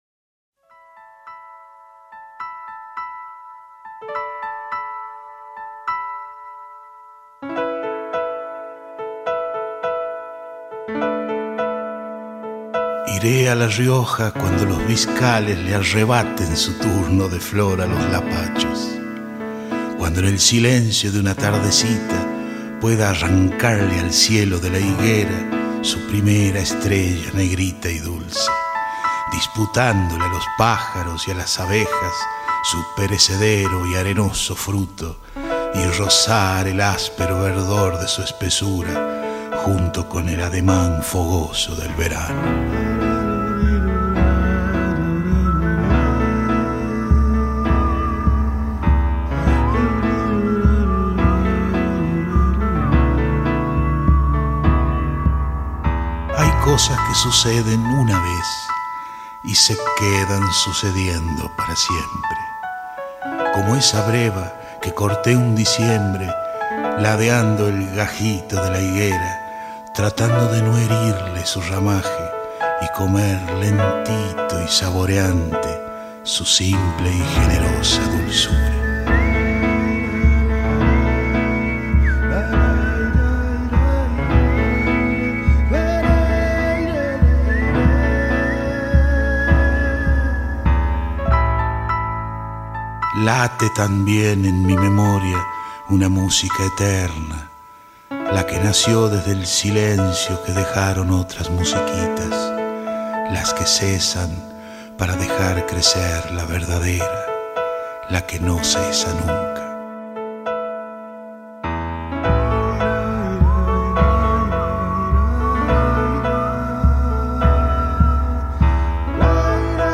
voz